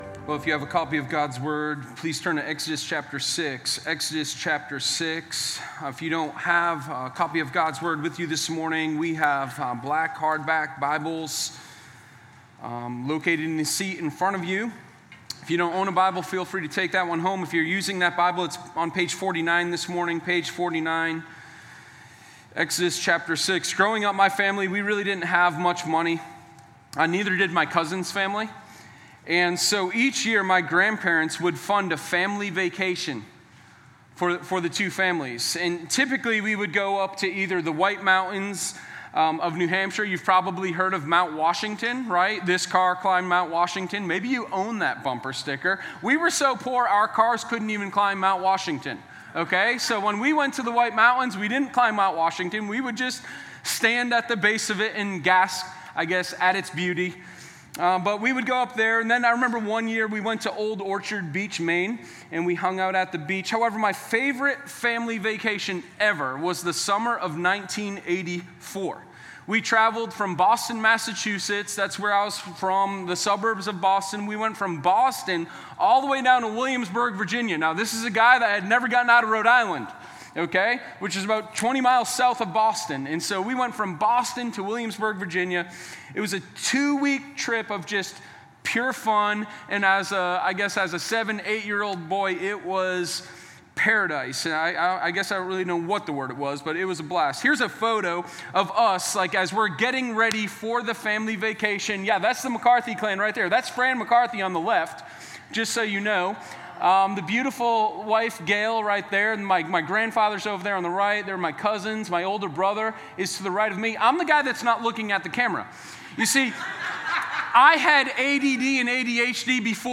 Sermon0311_AGodofPromise.mp3